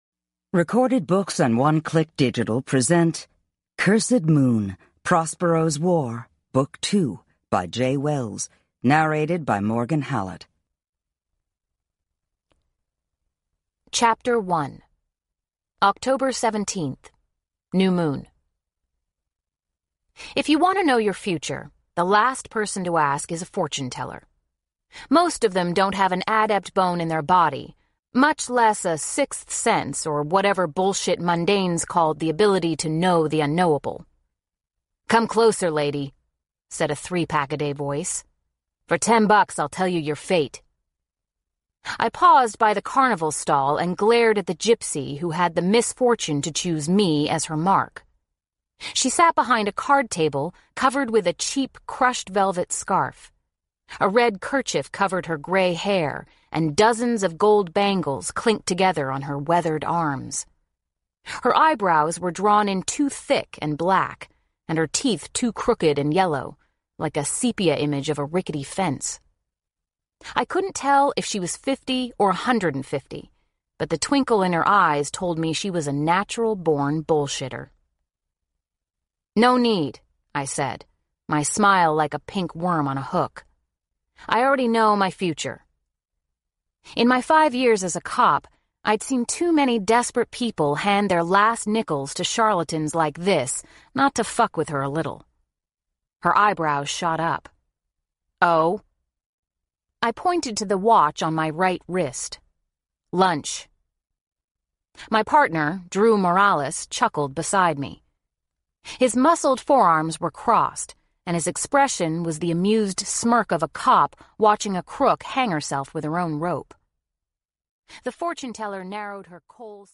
OverDrive MP3 Audiobook
Unabridged